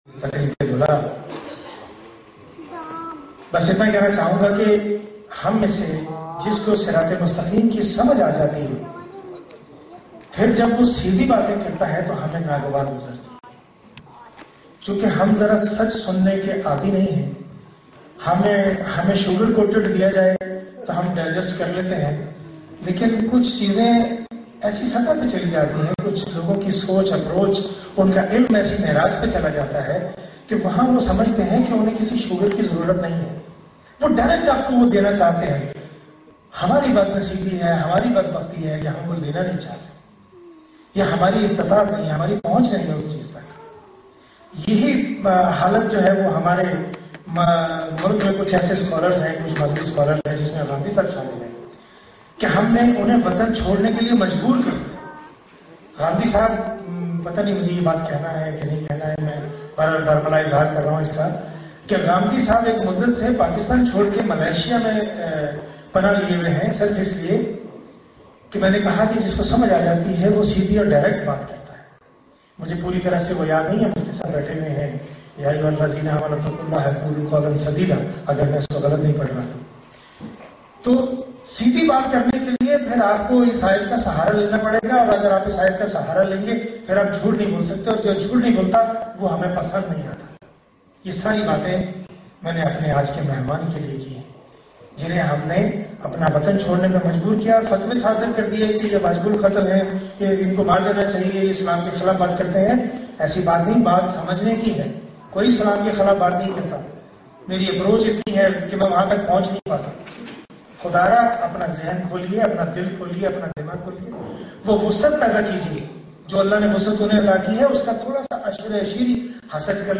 Javed Ahmad Ghamidi’s 14th Aug. 2014 speech, Oslo, Norway
The Independence Day event was organised by Pakistan Union Norway and took place in Lørenskog, Oslo.